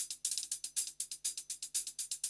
HIHAT LO12.wav